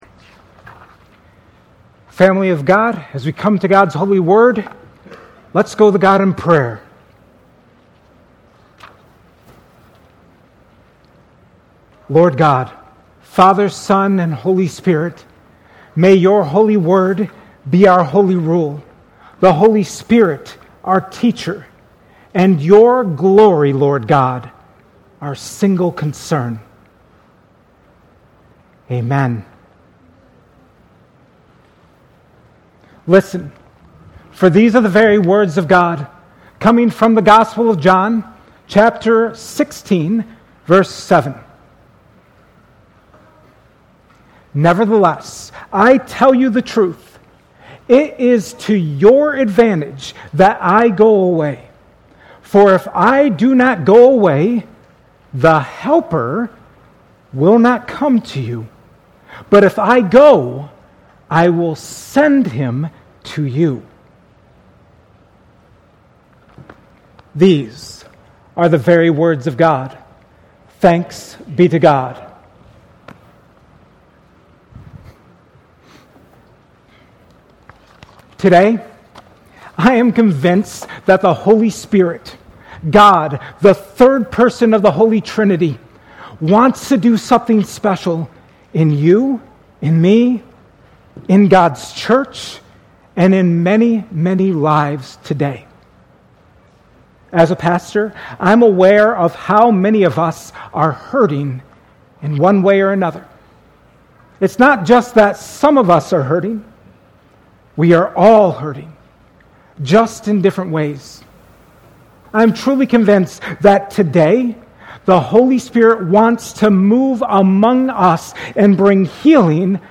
at Cornerstone Church on September 28, 2025.